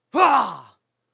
su_cheer3.wav